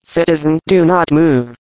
scanner
spch_attention2.ogg